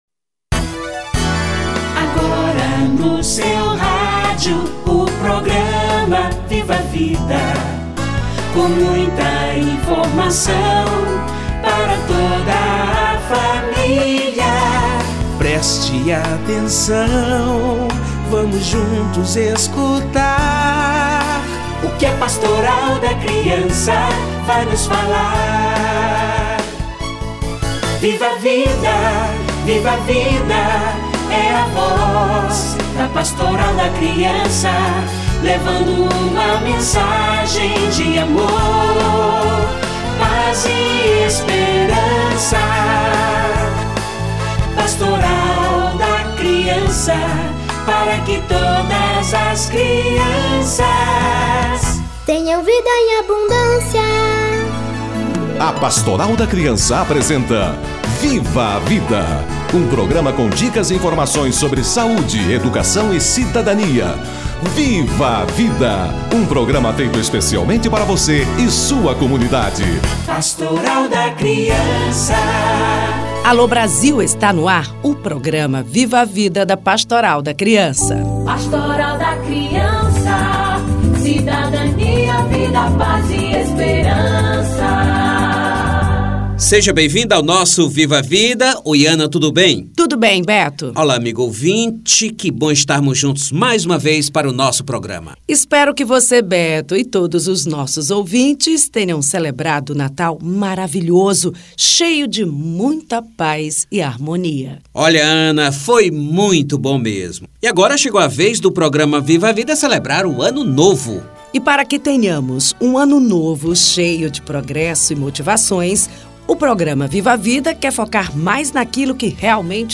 Ano Novo - Confraternização universal - Entrevista